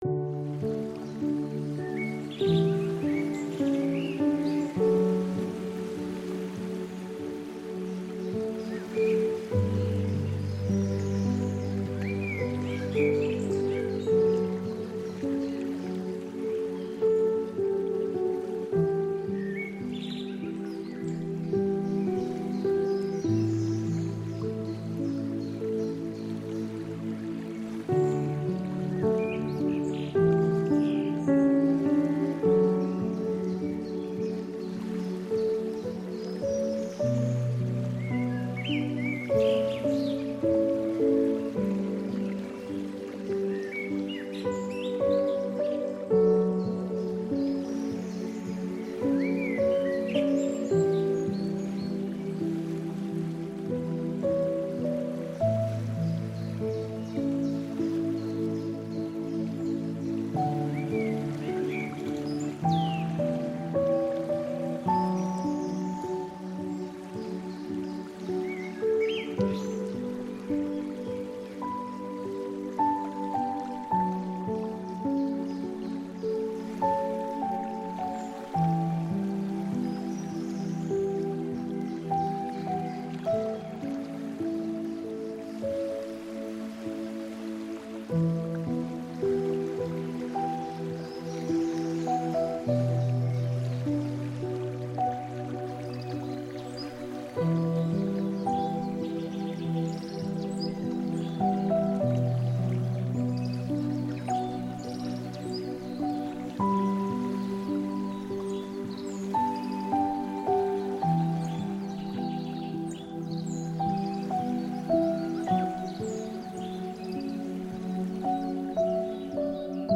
HERZ-GEIST-VEREINIGUNG: Waldabend-Regen beruhigt beide
Naturgeräusche